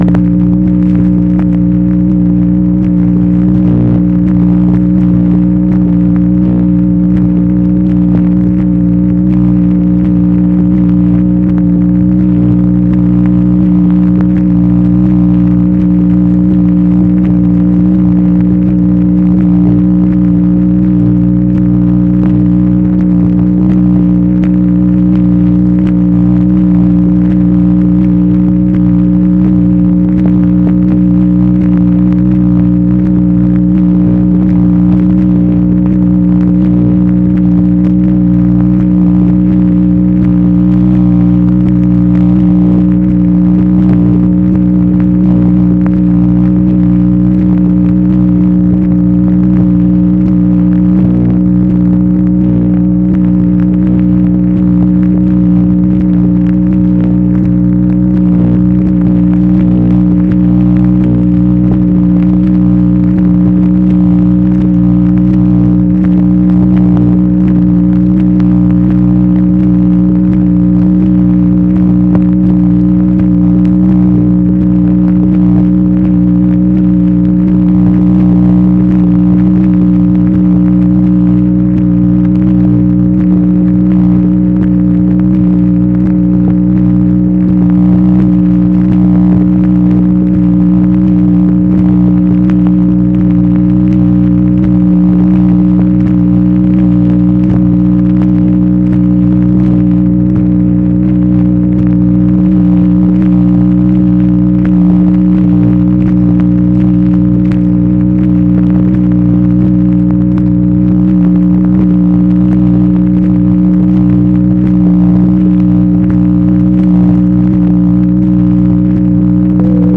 MACHINE NOISE, October 18, 1968
Secret White House Tapes | Lyndon B. Johnson Presidency